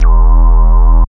74.05 BASS.wav